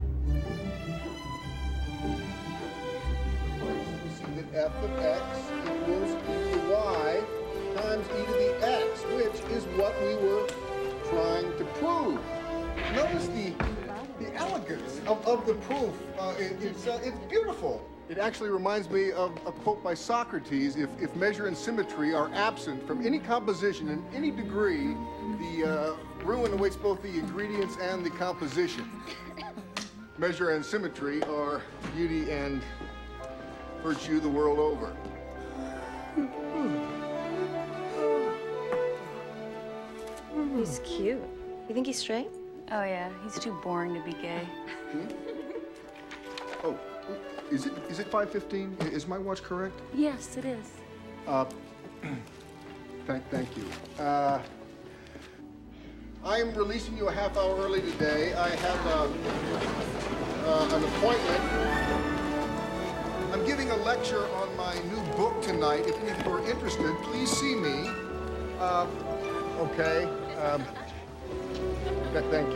On this page, I have only 5 sequence examples taken from actual movies.
The end of a calculus lecture.